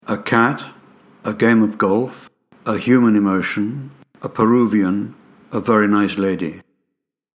Decimos a + sonido consonante y an + sonido vocal